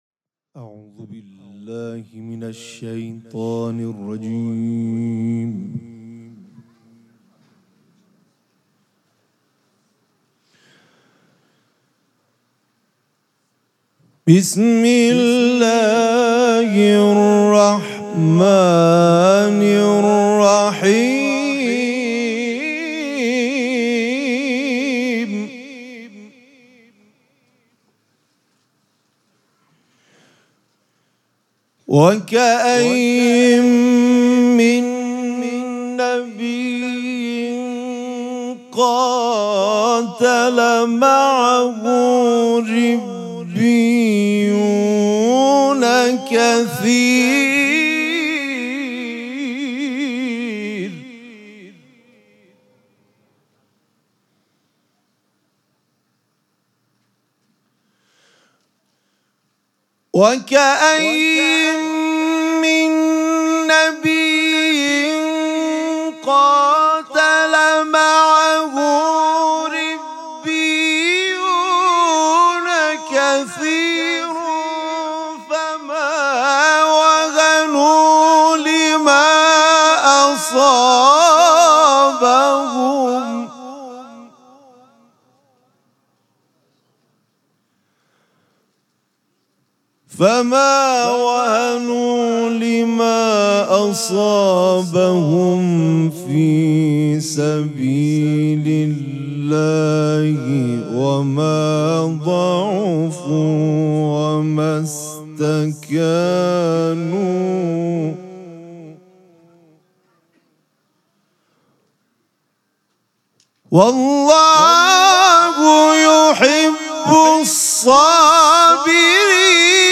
شب دوم مراسم جشن ولادت سرداران کربلا
حسینیه ریحانه الحسین سلام الله علیها
قرائت قرآن